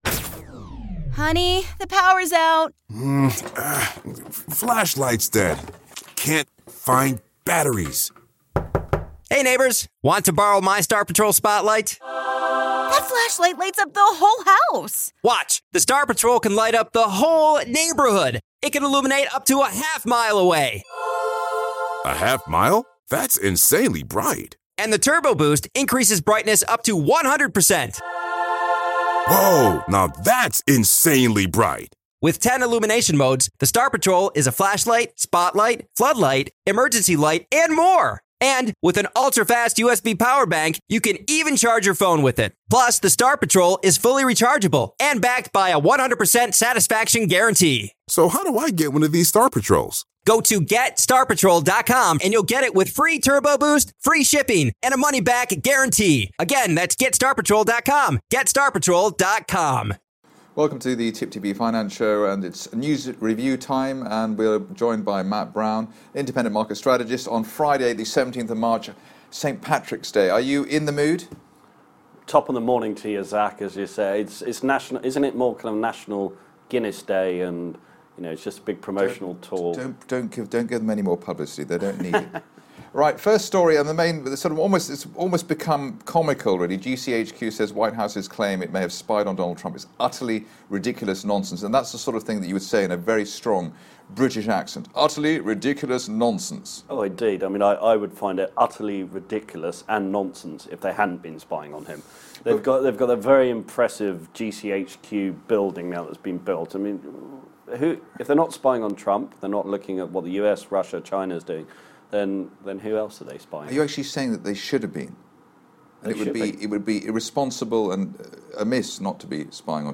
Newspaper review: GCHQ denies spying on Trump